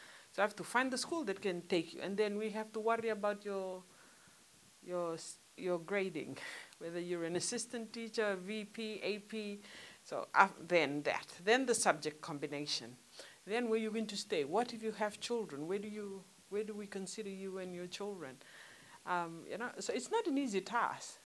PS Education Selina Kuruleca